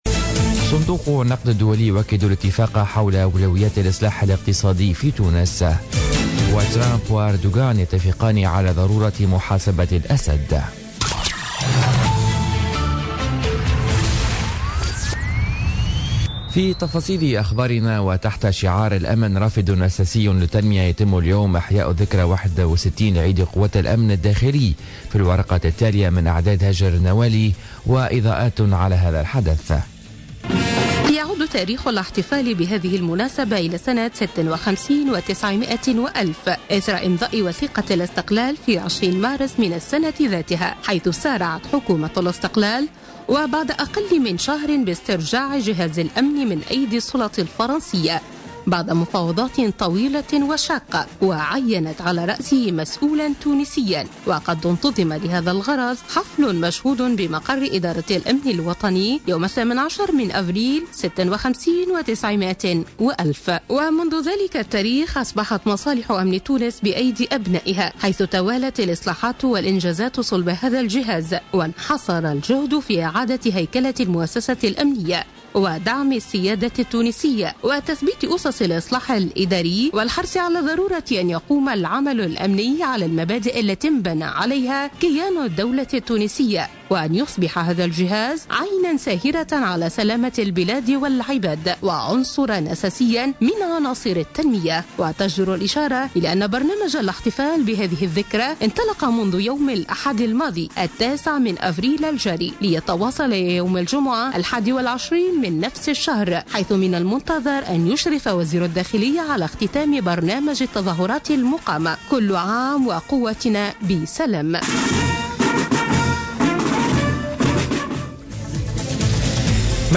نشرة أخبار السابعة صباحا ليوم الثلاثاء 18 أفريل 2017